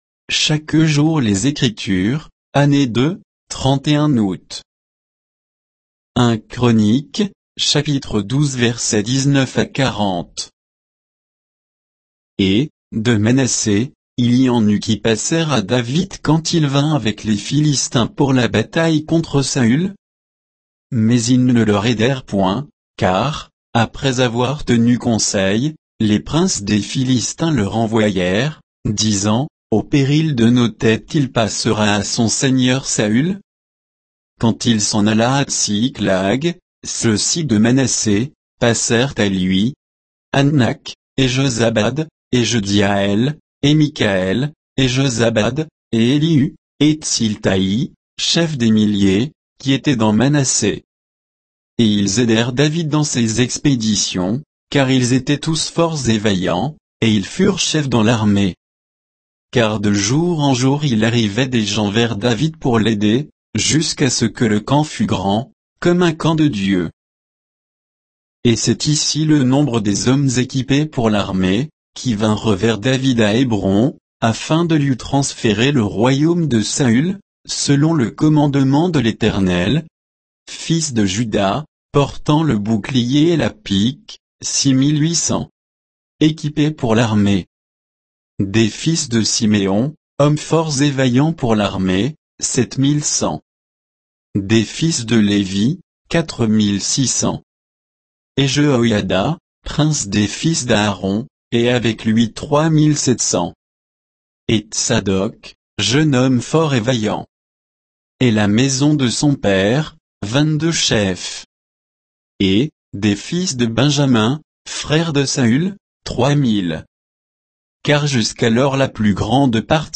Méditation quoditienne de Chaque jour les Écritures sur 1 Chroniques 12